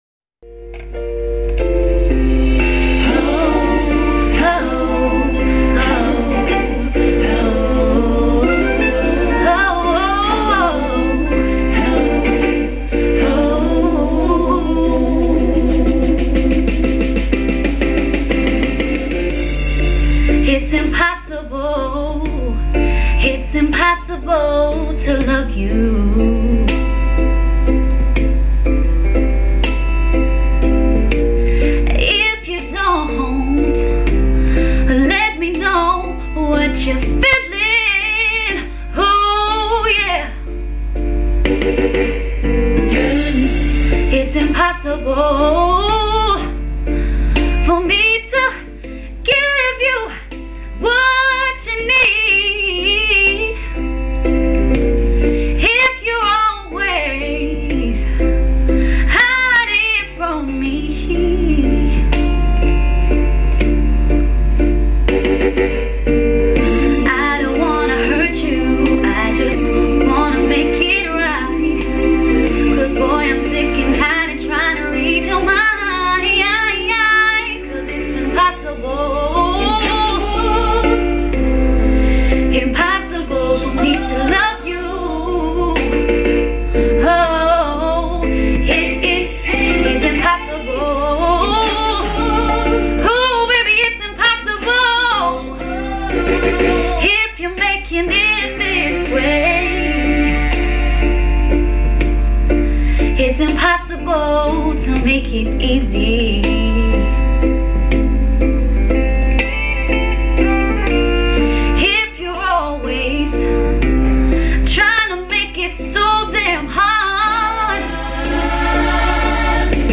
Dutch singer